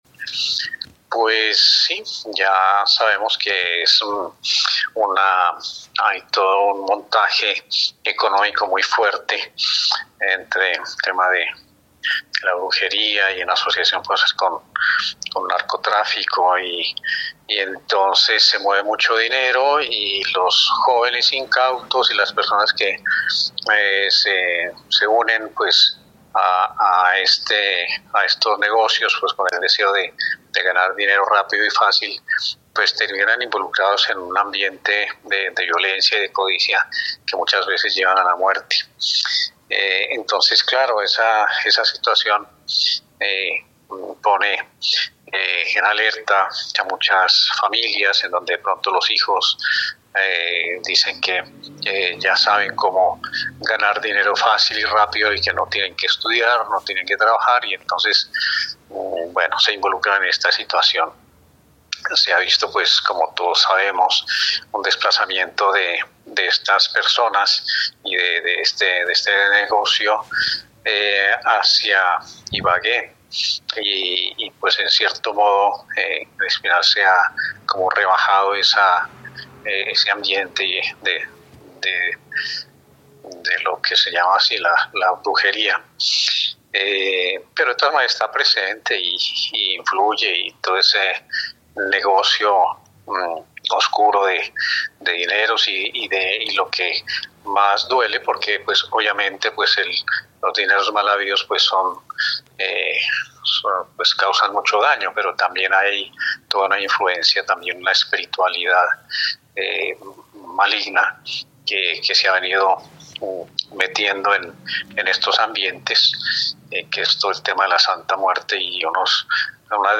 Escuche la entrevista con el obispo del Espinal, monseñor Miguel González .